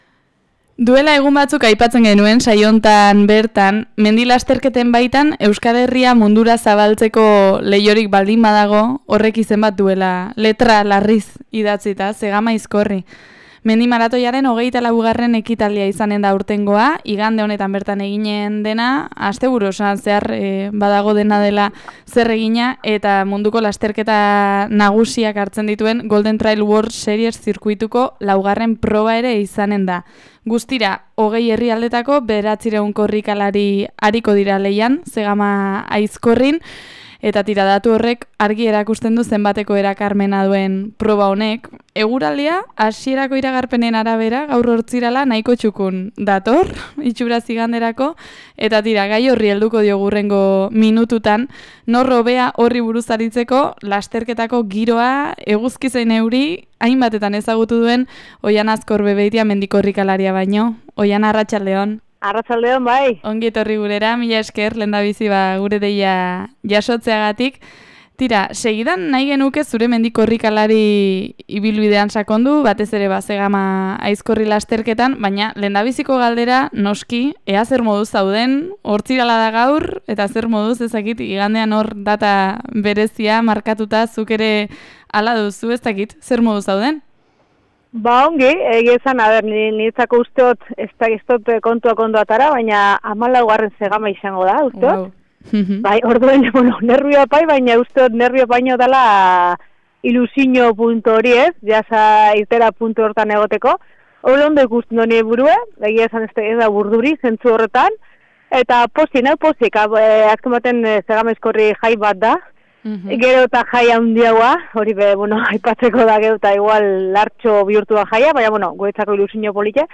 mendi lasterkaria gonbidatu dugu gurera horri buruz solasean aritzeko